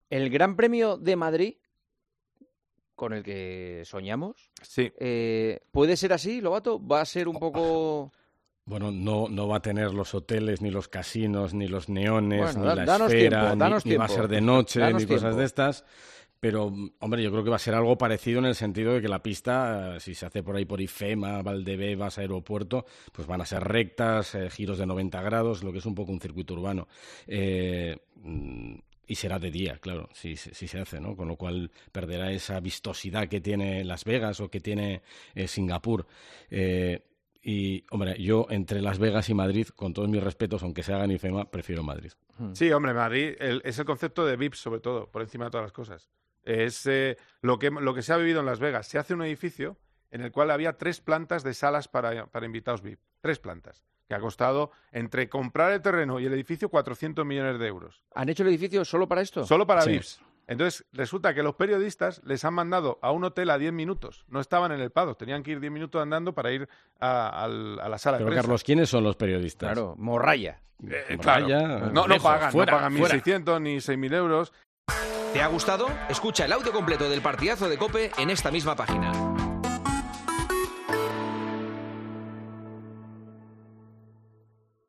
AUDIO: Después de un fin de semana muy convulso en Estados Unidos, Juanma Castaño le preguntó en El Partidazo de COPE si la cita en la capital de España puede...